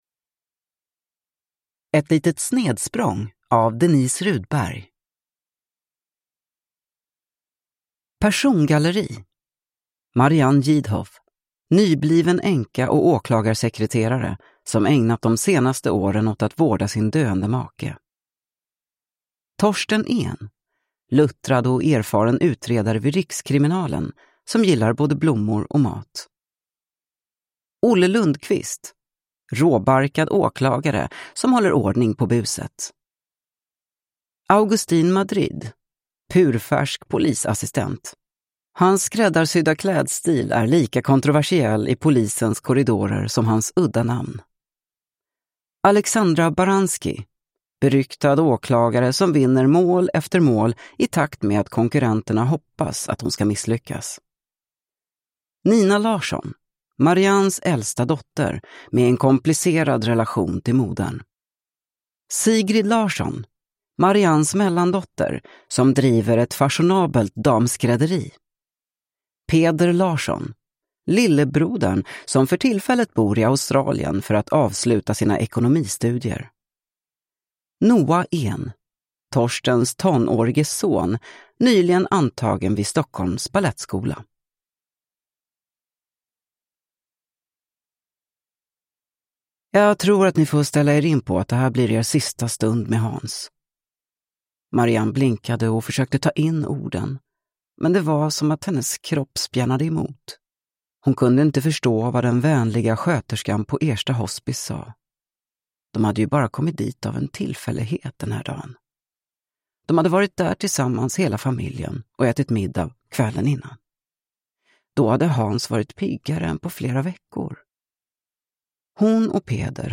Ett litet snedsprång – Ljudbok